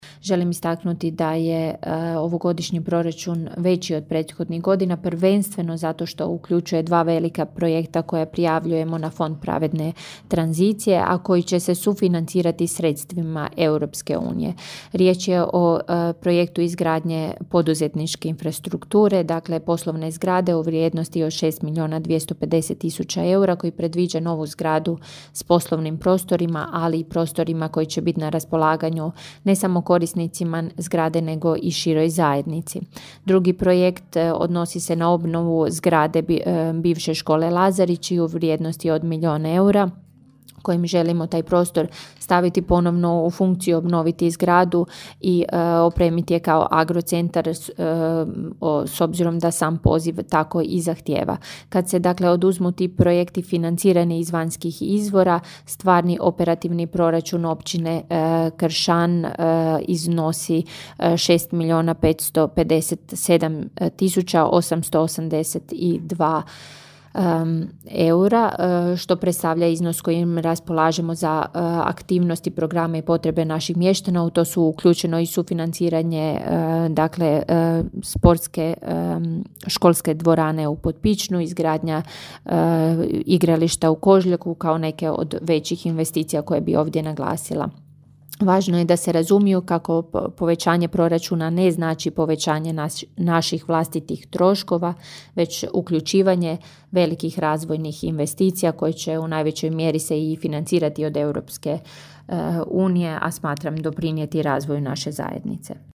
Proračun Općine Kršan za iduću godinu planiran je u visini od 13.807,882 eura, rečeno je sinoć na sjednici Općinskog vijeća na prvom čitanju proračuna. Govori općinska načelnica Ana Vuksan: (